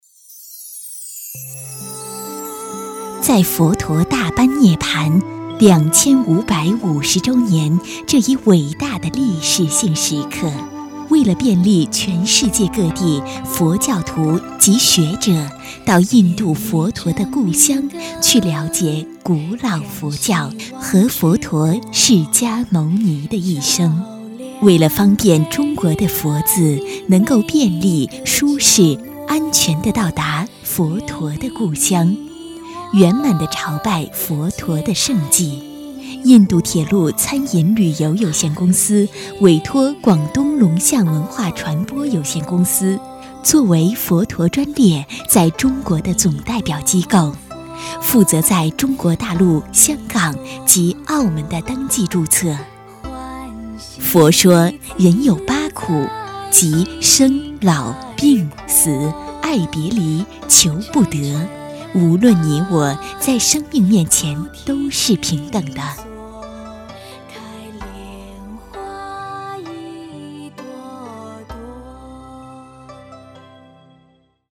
女国65_专题_宗教_佛_讲述.mp3